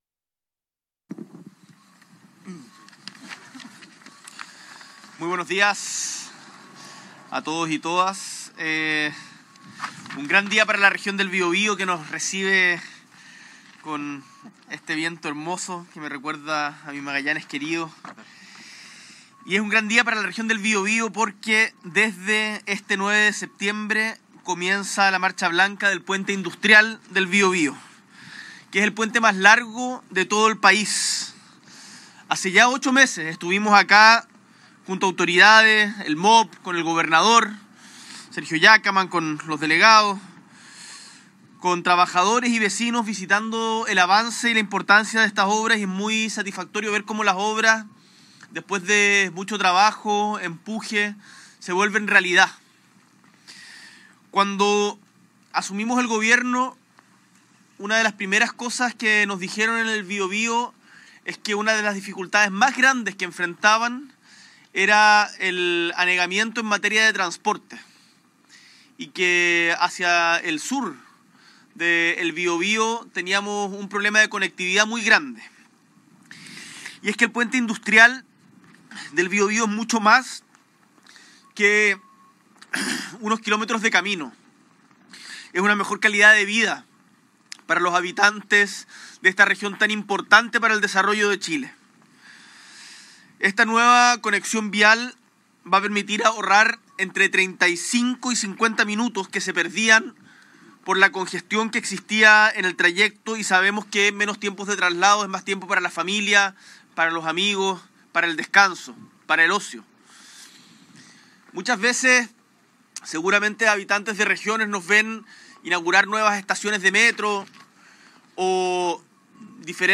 S.E el Presidente de la República, Gabriel Boric Font, encabeza el hito de apertura para la puesta en marcha del Puente Industrial, nueva conexión vial entre las comunas de San Pedro de la Paz y Hualpén en la Región del Biobío